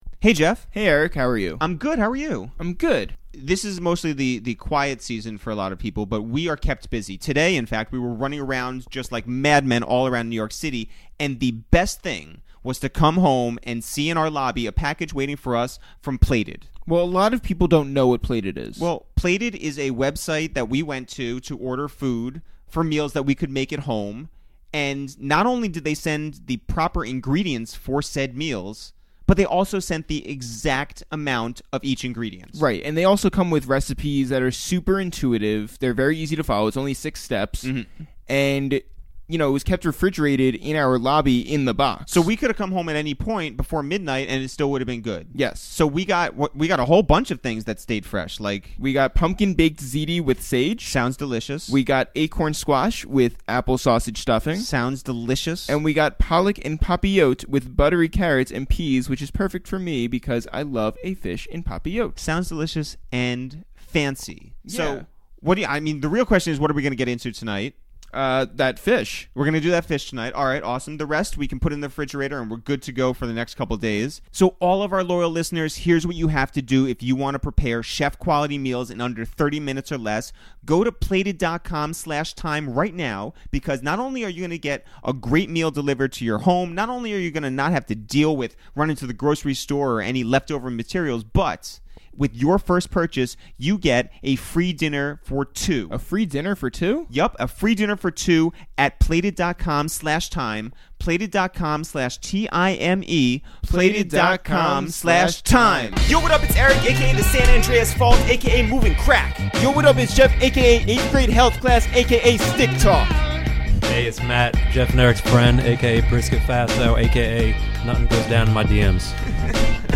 Over a great BBQ spread, Gotti spoke on his relationships with Jeezy, DJ Khaled, and Cash Money, the perks of being neighbors with Penny Hardaway, and why you can't hire family to work at your restaurant. We discussed his record deals with TVT, RCA and Epic, what it was like to meet Jay Z for the first time in Miami, and the best places he hid his hustling money.